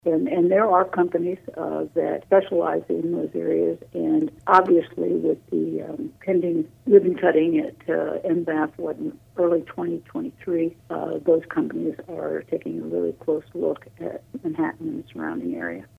Governor Laura Kelly spoke with News Radio KMAN this week during an exclusive interview heard Wednesday on KMAN’s In Focus.